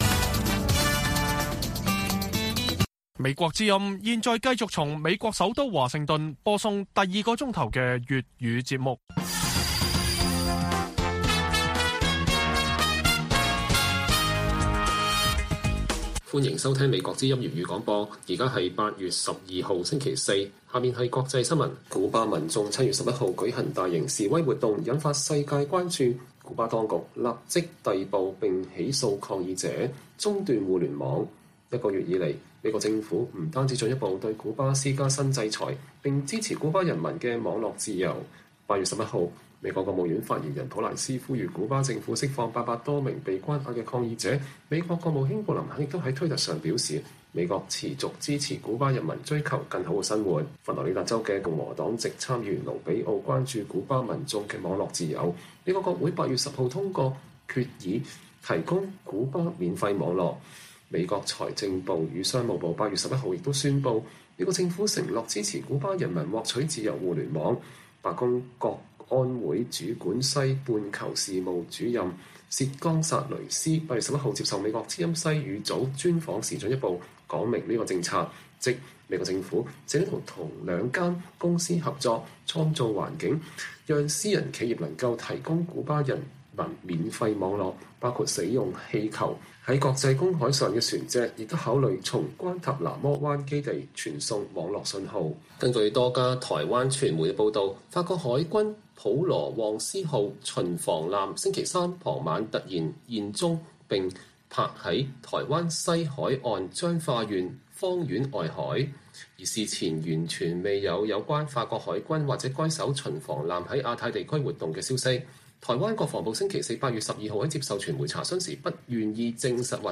粵語新聞 晚上10-11點: 古巴民眾示威事件周月美國持續呼籲釋放民眾與網絡自由